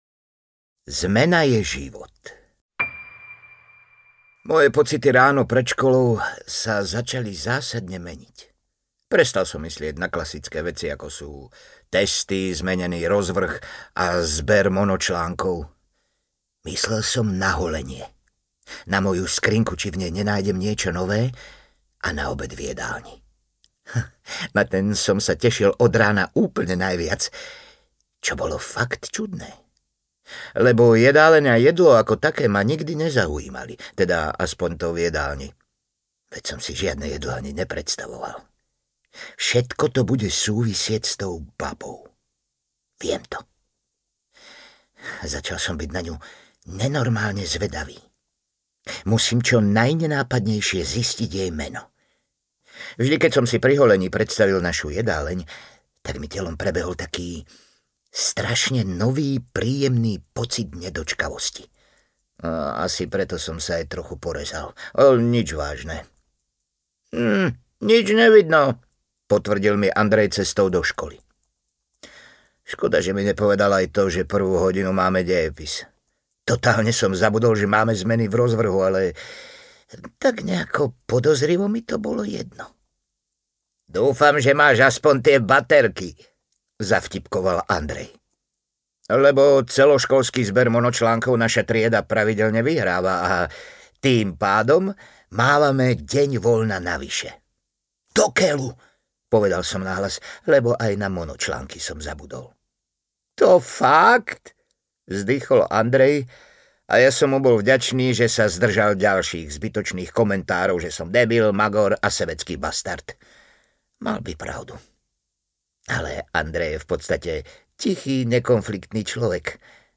Audio knihaTraja kamoši a fakt napínavý príbeh
Ukázka z knihy
• InterpretRichard Stanke